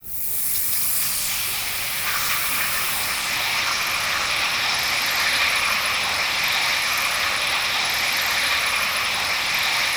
ATMOPAD29 -LR.wav